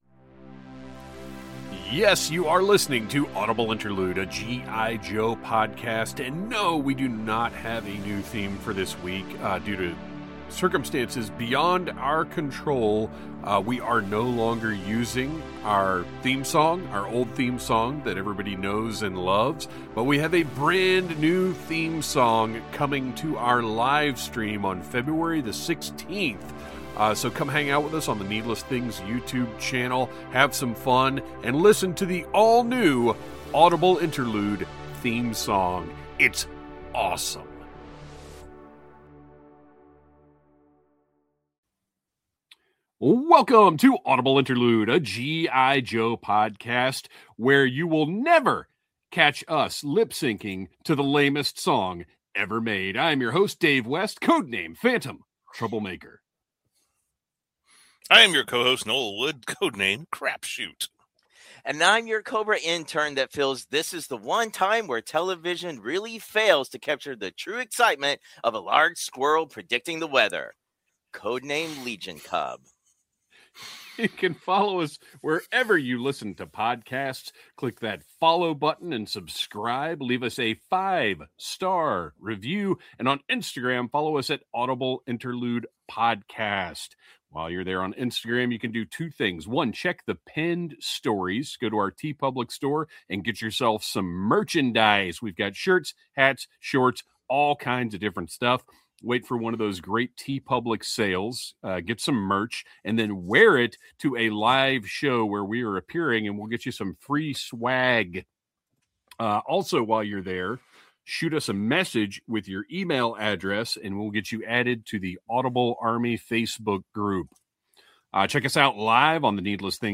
We're recruiting you to listen to be a part of the Audible Interlude experience, hosted by three lifelong Joe fans. Our mission is to look at every era of GI Joe from the classic 12" Joes to today's modern Classified Series.